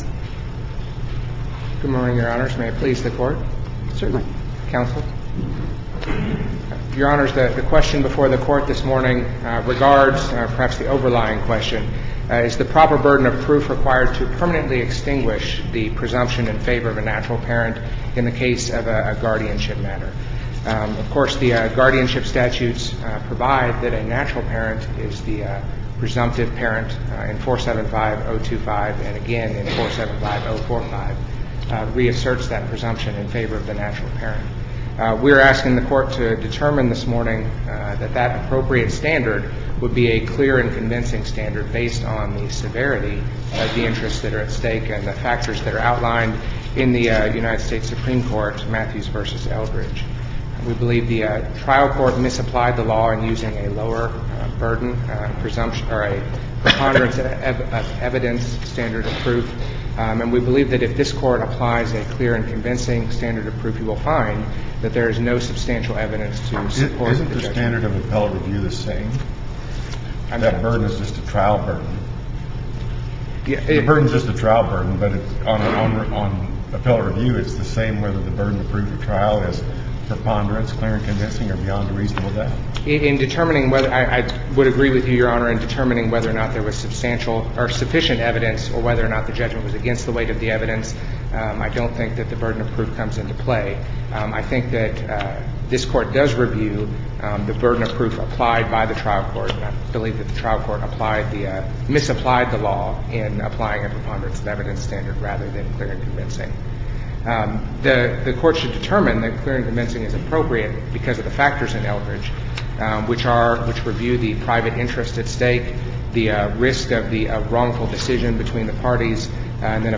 MP3 audio file of arguments in SC95890